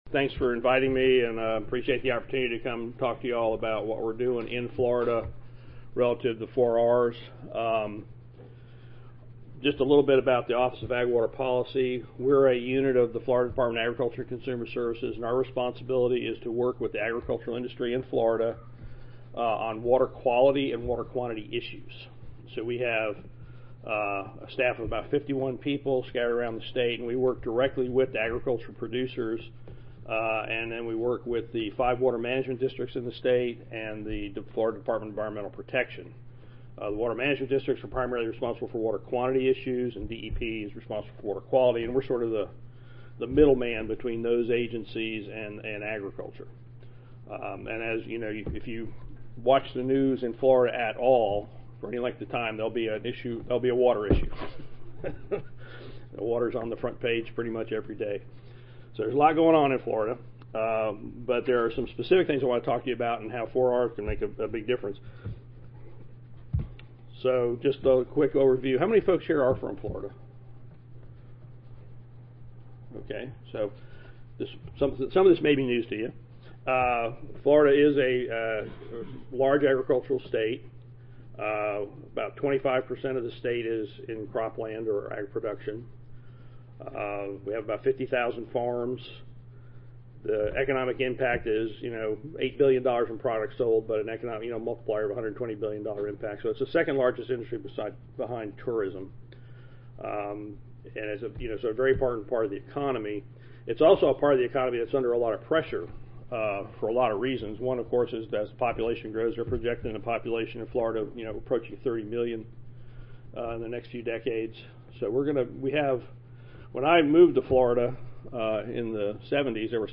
Recorded Presentation
In this session, a panel of speakers will present on the interaction of soil health and 4R practices.
Three graduate student presenters will give Five-Minute Rapid presentations on recent soil health and 4R research, focusing on providing the CCA audience with a take home item from their projects.
The final panelist will discuss how 4R nutrient stewardship is being implemented in Florida. The remaining time in the session will be for questions and group discussion.